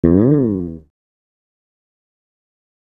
D SLIDE.wav